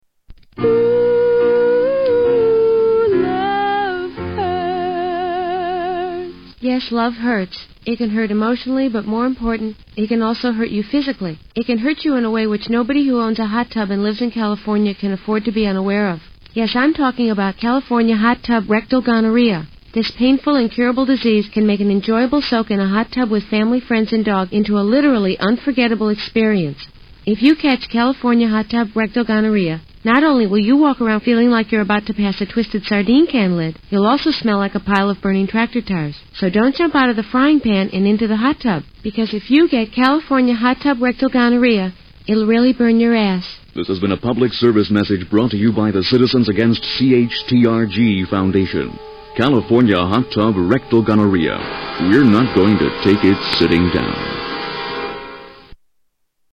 Tags: Comedy Commercials Funny Commercials Commercials Comedy Funny